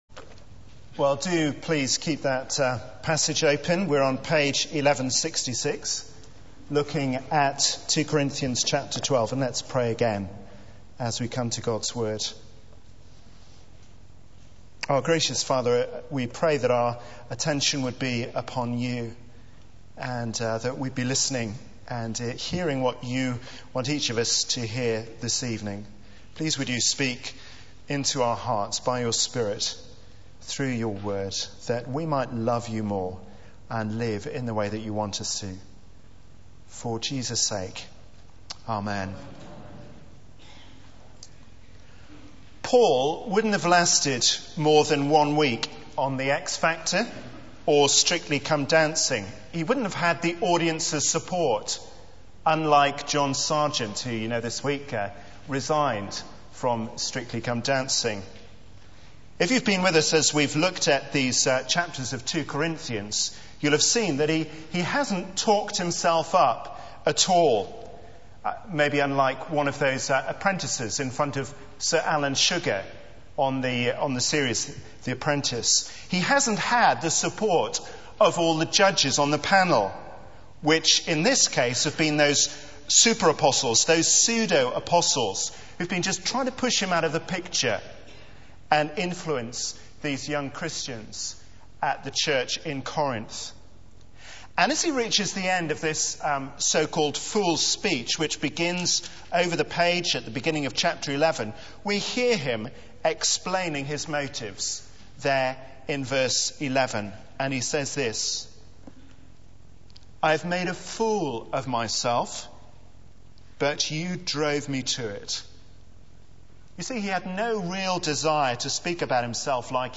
Media for 9:15am Service on Sun 23rd Nov 2008 18:30 Speaker: Passage: 2 Cor 12:11-21 Series: Meekness is Strength Theme: Gladly Spend or be Spent Sermon slide Open Search the media library There are recordings here going back several years.